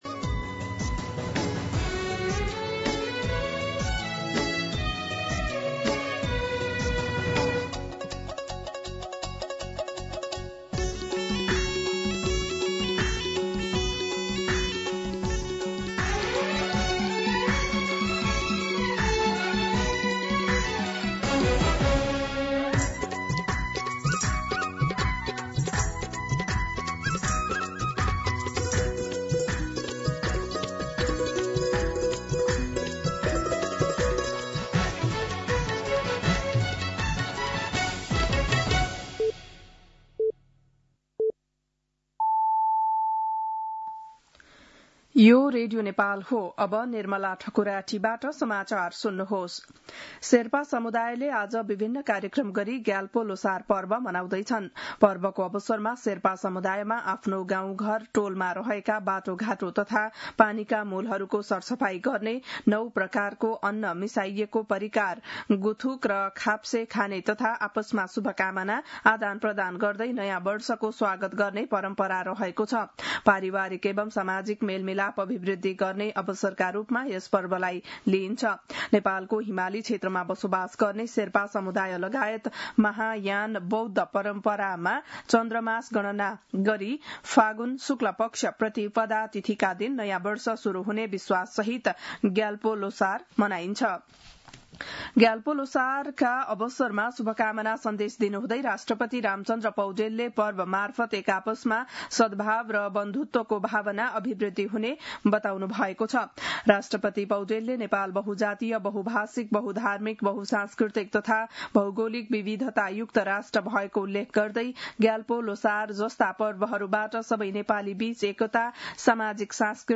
बिहान ११ बजेको नेपाली समाचार : ६ फागुन , २०८२
11-am-Nepali-News-5.mp3